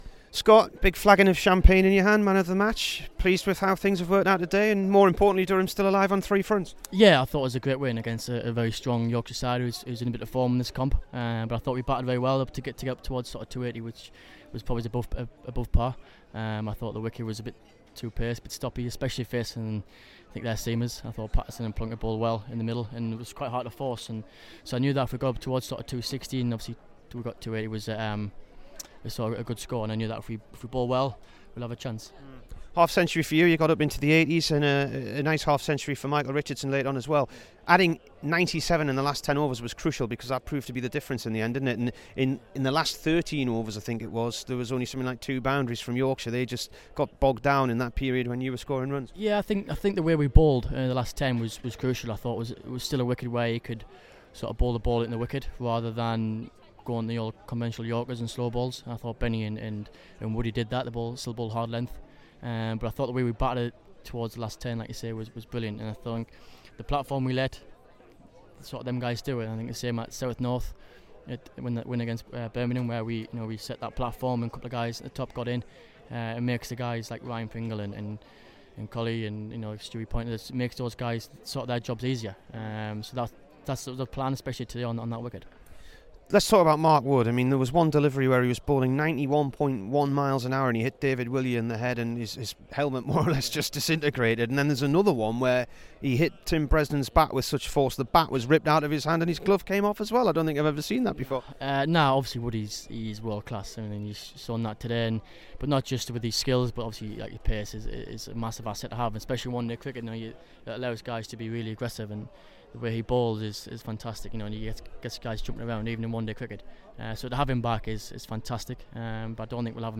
SCOTT BORTHWICK INT